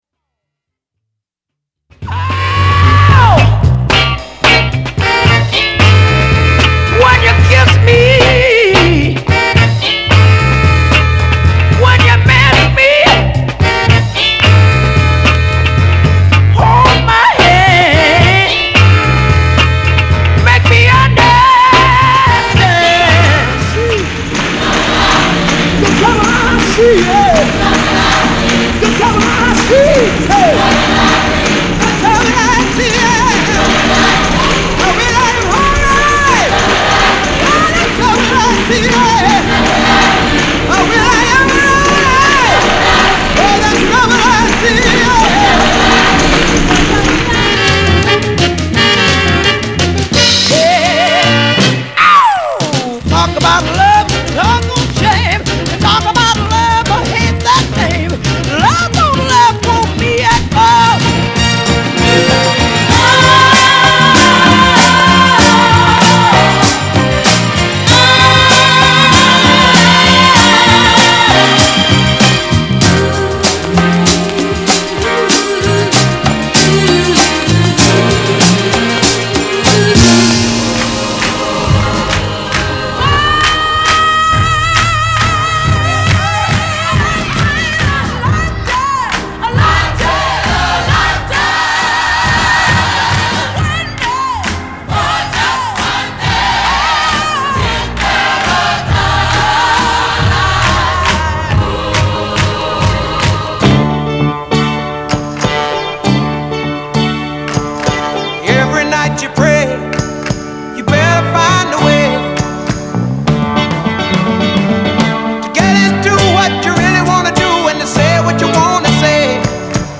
category Vocal
Yahoo Bid Music Vinyl Records Jazz Vocal